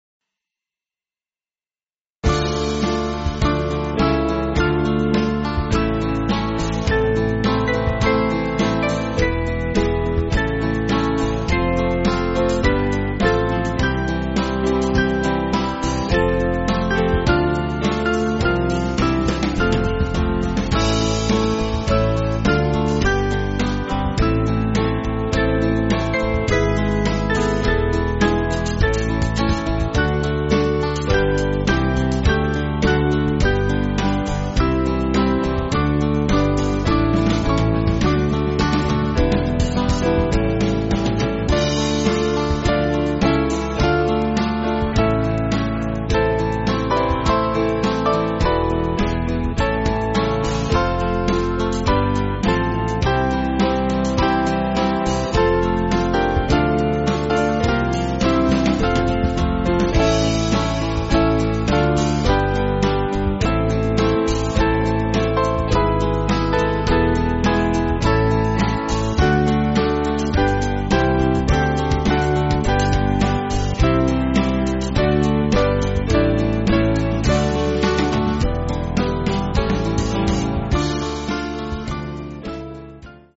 Small Band
(CM)   4/Dm